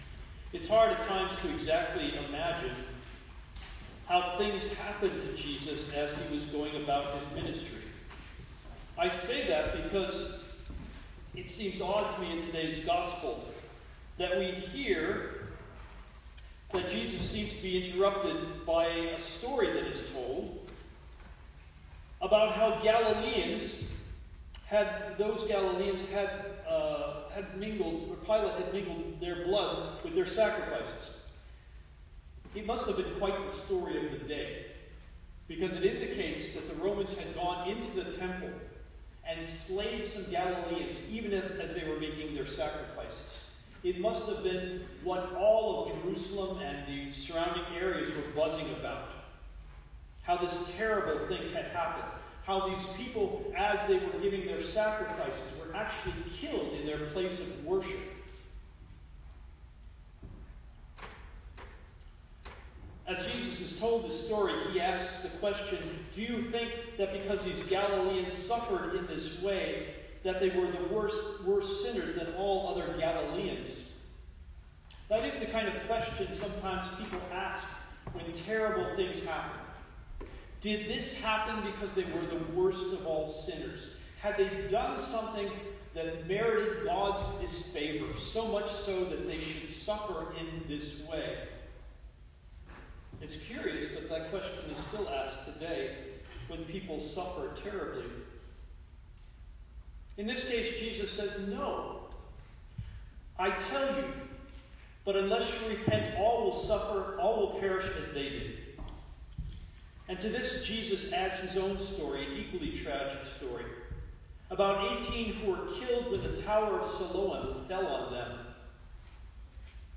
Sermon3-24-CD.mp3